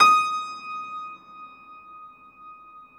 53c-pno19-D4.wav